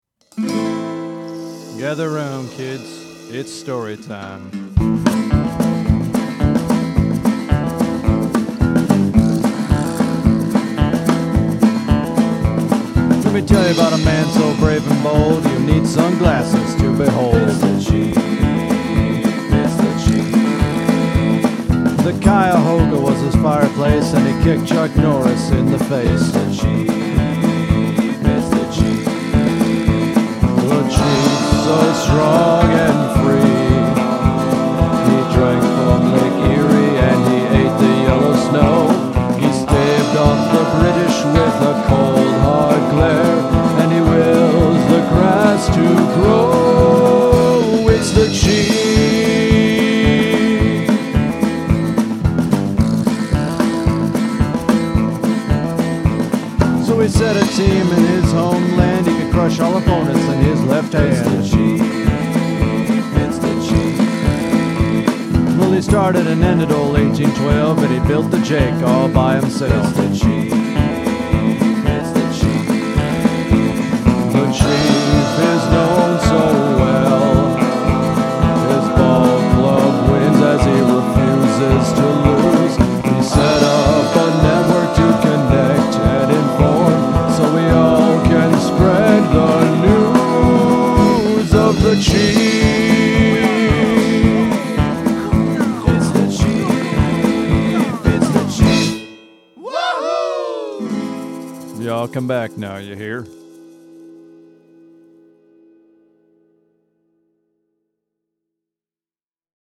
I set about doing so the only way I knew: I gave him Chuck Norris-like qualities and set it in a rambling country-style musical backdrop.